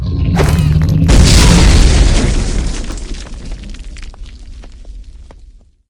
grenade2.ogg